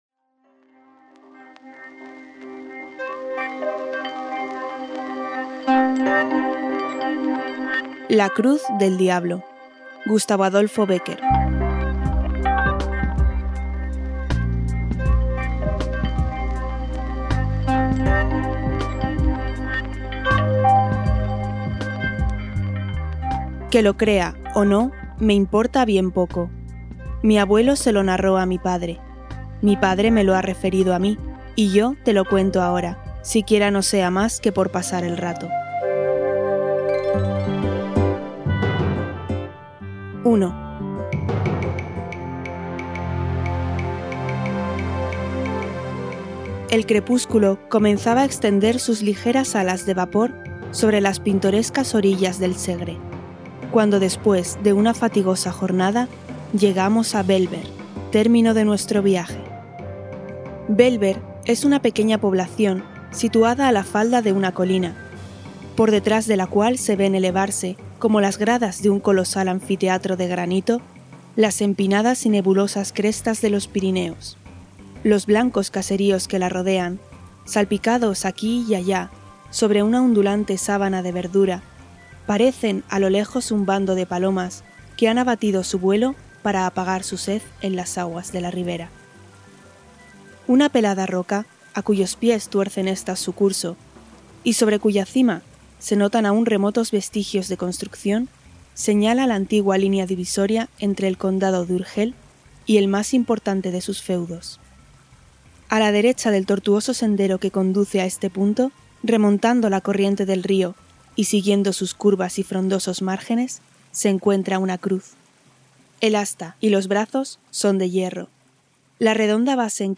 Leyenda
Música: Olga Scotland (cc:by)